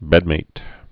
(bĕdmāt)